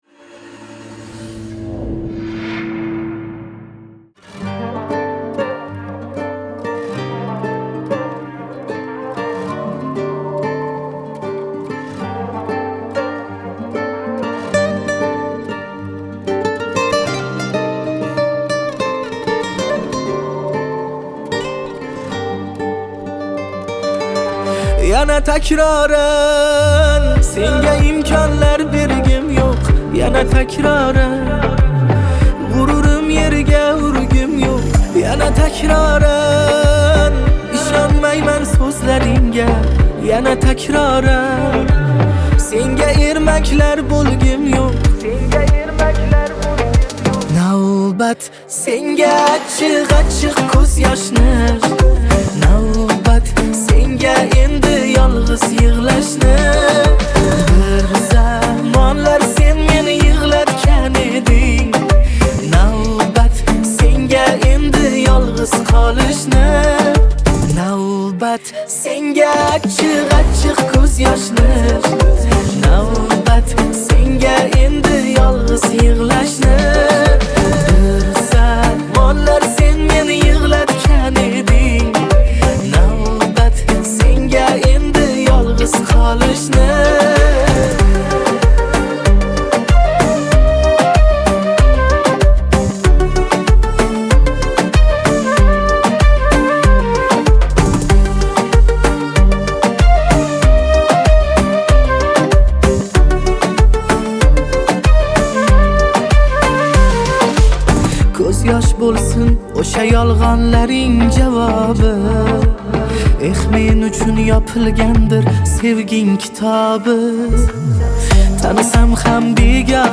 Жанр: Узбекские треки